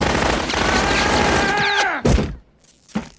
yelling sound effect eight.ogg
Original creative-commons licensed sounds for DJ's and music producers, recorded with high quality studio microphones.
Channels Stereo
[yelling-sound-effect]_(8)_2ea.mp3